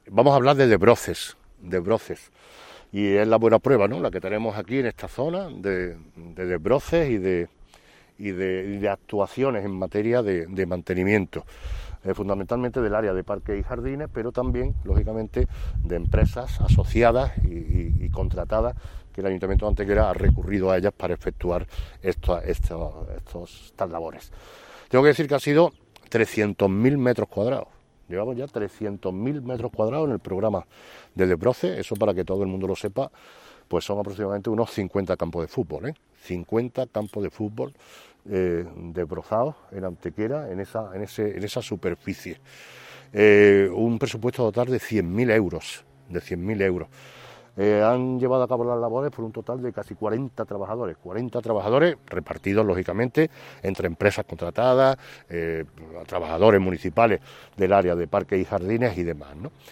El alcalde de Antequera, Manolo Barón, y la teniente de alcalde delegada de Mantenimiento, Teresa Molina, han informado hoy en rueda de prensa sobre el desarrollo del Plan Municipal de Desbroce 2021 que ya se encuentra en fase de mantenimiento tras su acometida desde el pasado mes de marzo.
Cortes de voz